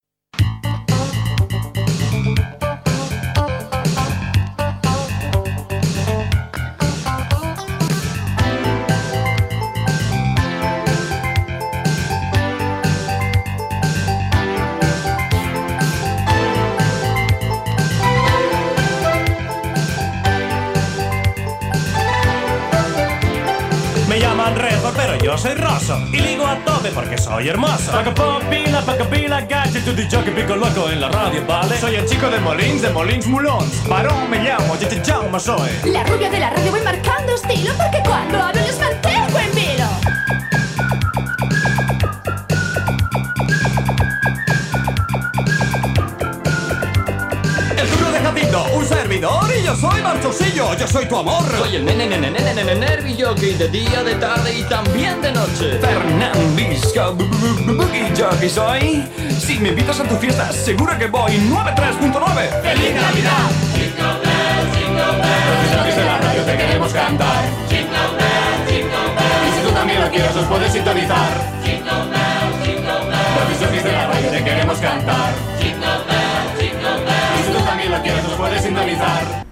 Nadala dels DJ
Musical